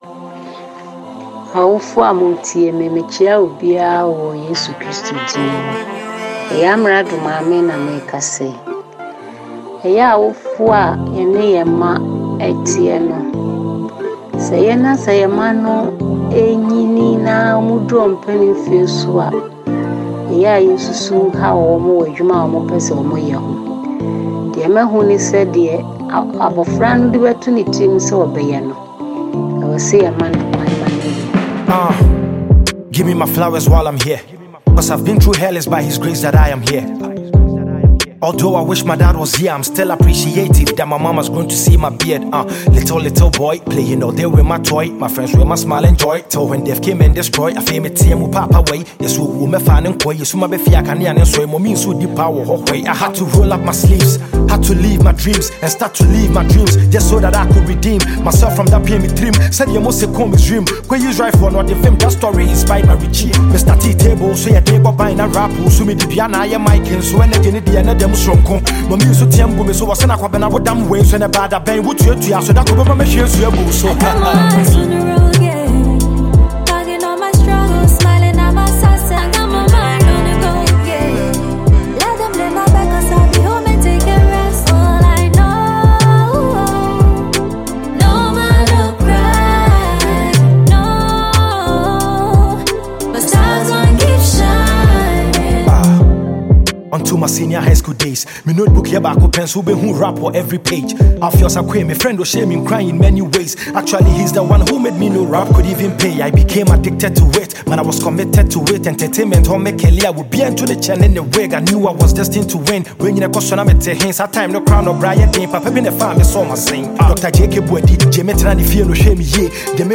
Ghana MusicMusic
Ghanaian rapper